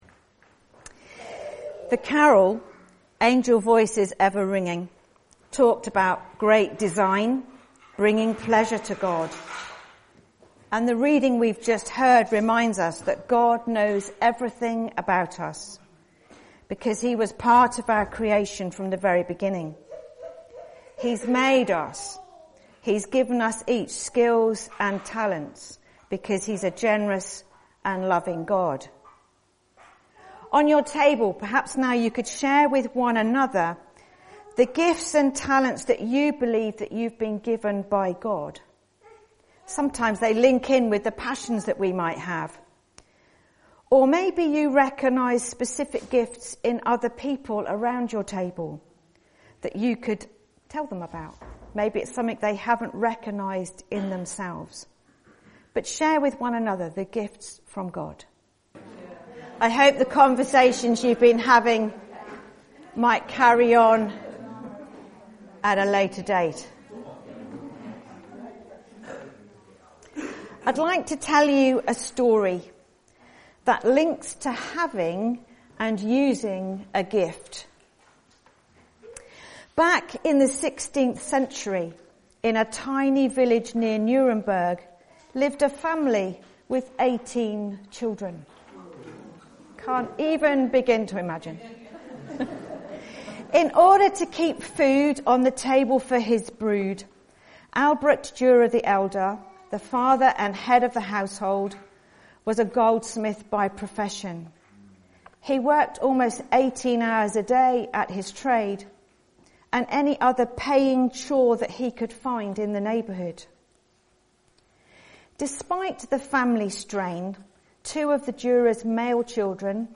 Sermon (Audio) - Well Street United Church